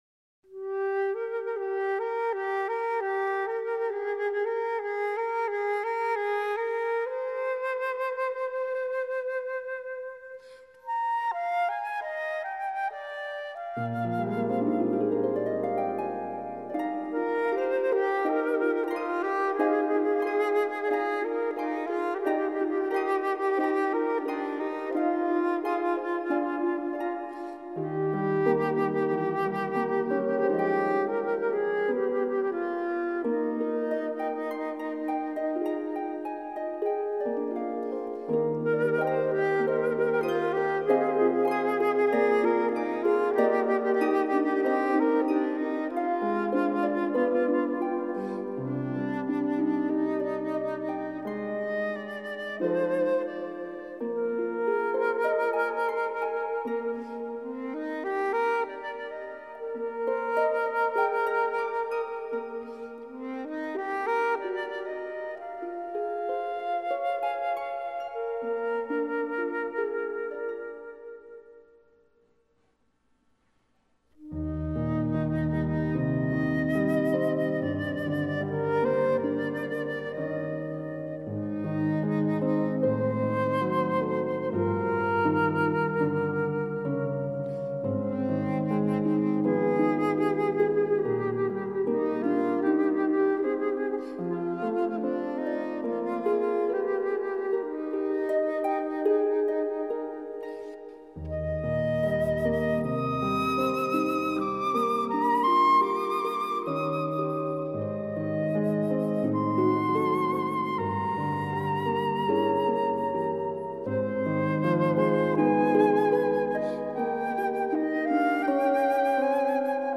這張CD是他首次跨界流行樂,
在輕盈的豎琴和柔和細膩管絃樂伴奏下,